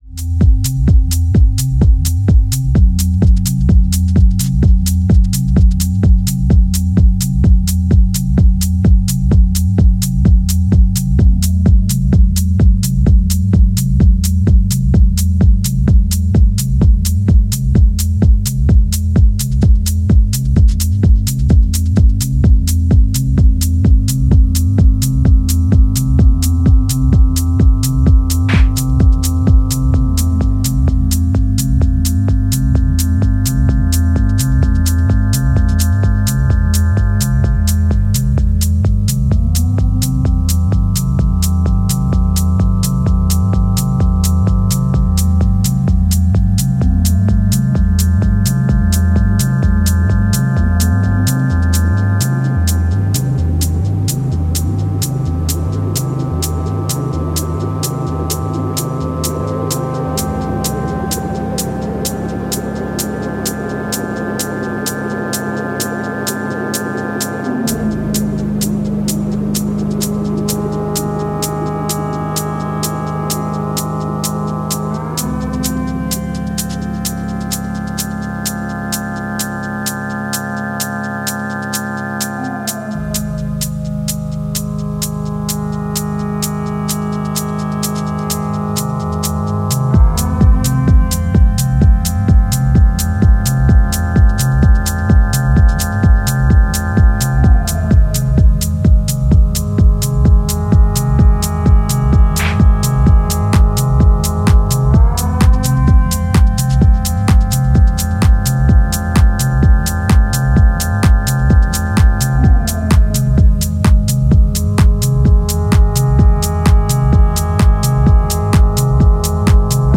暖かくまろやかなサブベースの壁、厳かに反復されるストリングスドローンが美しい